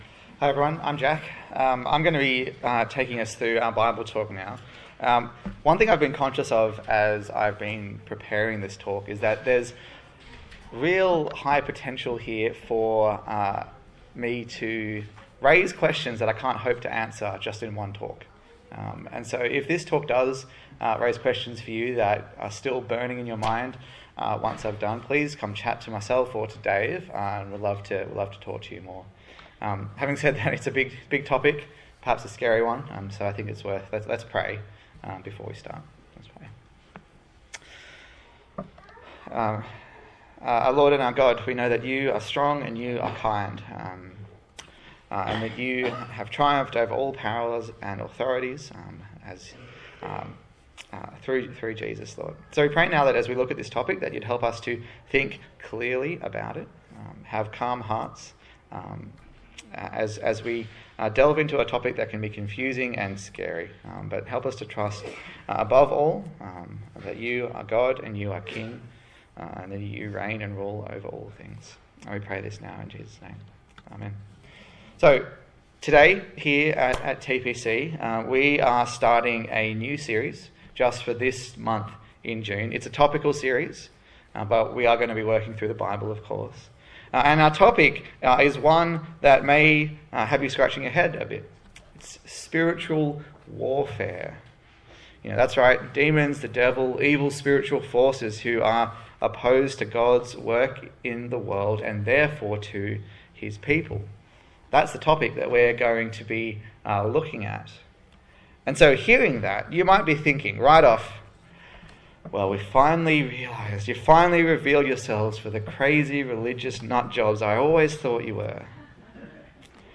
Full Armour of God Passage: Ephesians 6:10-13 Service Type: Morning Service